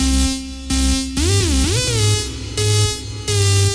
描述：好的循环。
标签： 128 bpm Weird Loops Fx Loops 647.01 KB wav Key : C
声道立体声